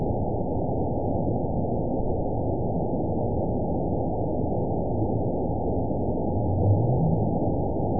event 912632 date 03/30/22 time 16:43:45 GMT (3 years, 1 month ago) score 9.43 location TSS-AB02 detected by nrw target species NRW annotations +NRW Spectrogram: Frequency (kHz) vs. Time (s) audio not available .wav